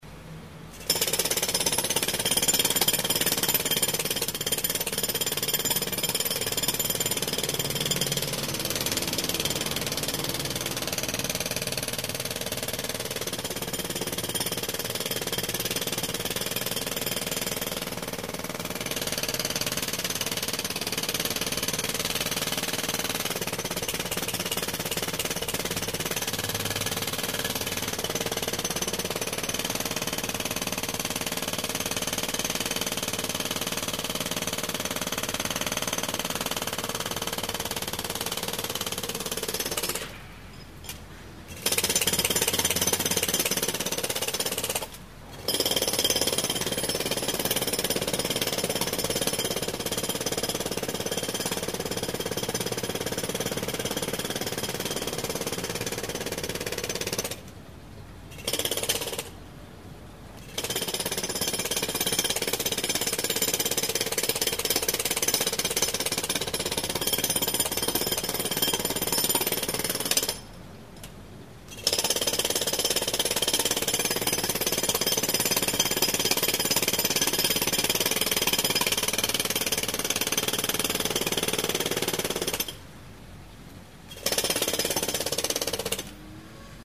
Звуки отбойного молотка
Отбойный молоток в действии